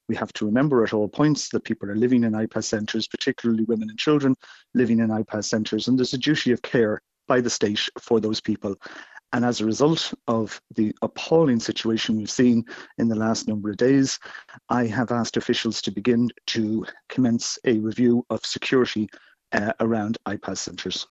Colm Brophy says the state has a duty of care to those in IPAS centres: